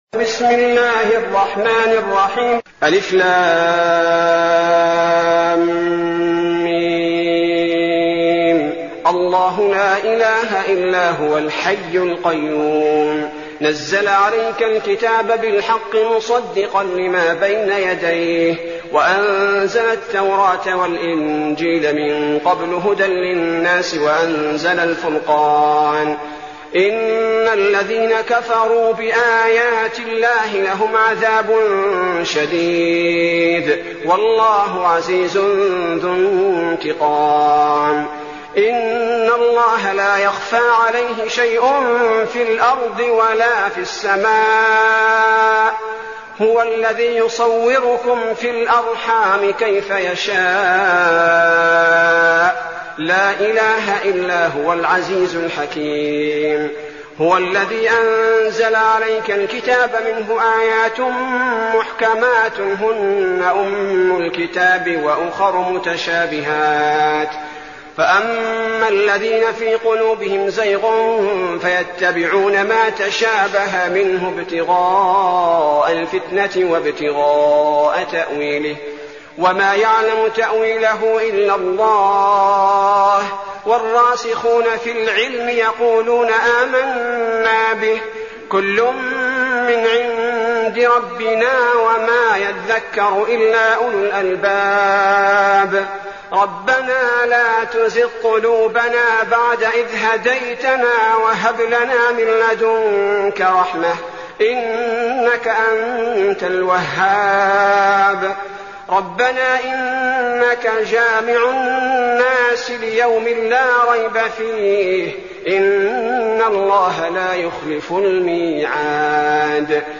المكان: المسجد النبوي الشيخ: فضيلة الشيخ عبدالباري الثبيتي فضيلة الشيخ عبدالباري الثبيتي آل عمران